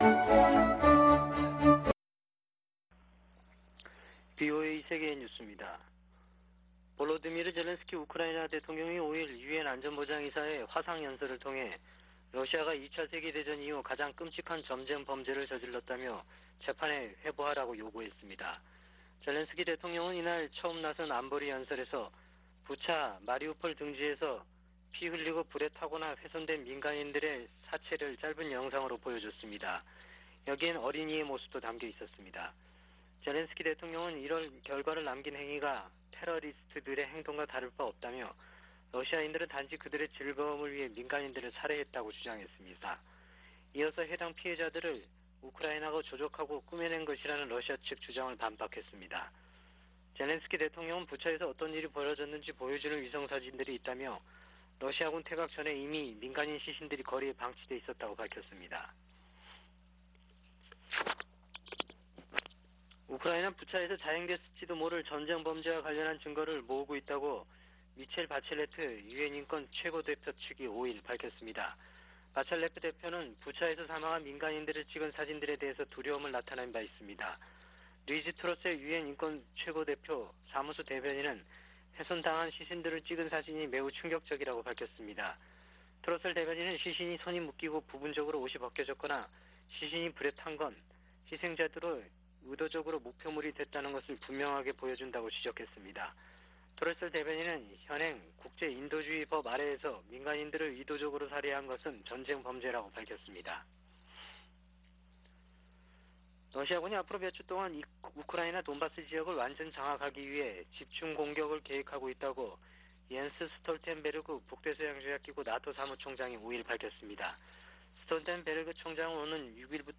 VOA 한국어 아침 뉴스 프로그램 '워싱턴 뉴스 광장' 2022년 4월 6일 방송입니다. 미 국무부는 한국의 윤석열 차기 정부가 전략동맹 강화를 언급한 것과 관련해 “한국은 중요한 조약 동맹”이라고 말했습니다. 미·한 북핵대표가 북한의 최근 ICBM 발사에 대한 새 유엔 안보리 결의안 추진 의사를 밝혔습니다.